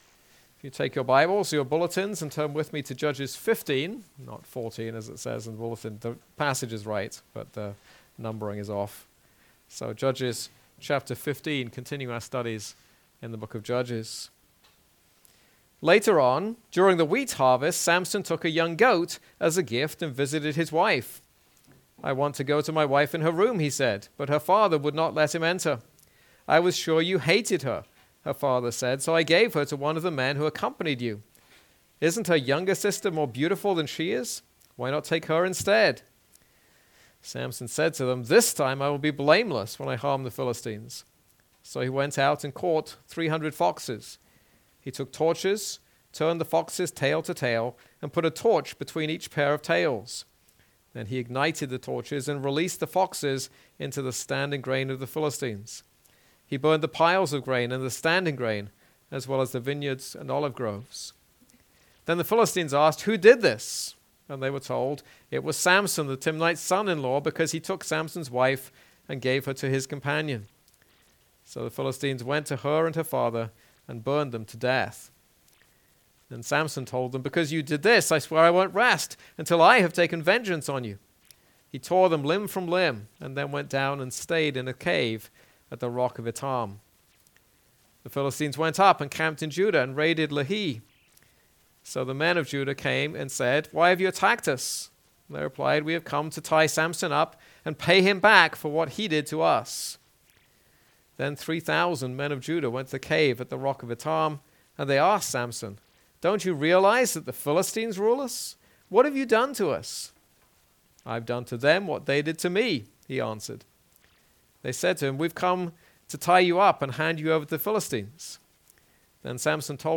This is a sermon on Judges 15.